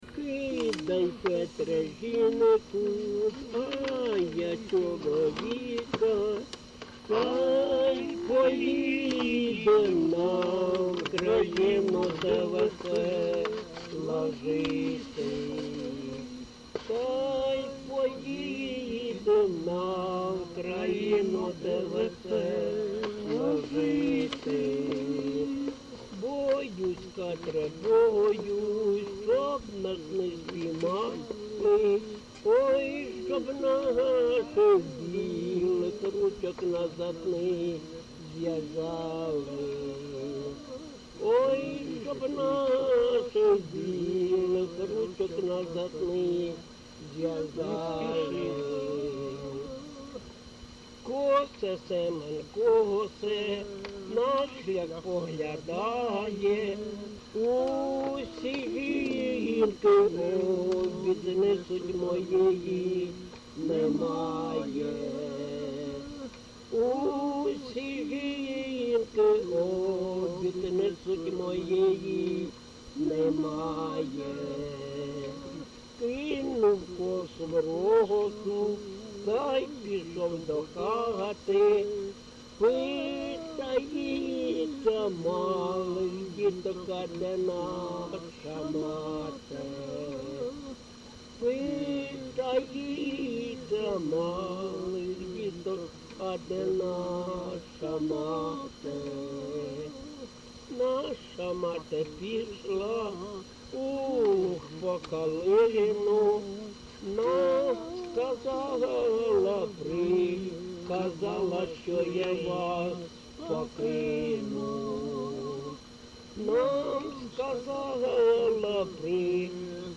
ЖанрПісні з особистого та родинного життя
Місце записус. Клинове, Артемівський (Бахмутський) район, Донецька обл., Україна, Слобожанщина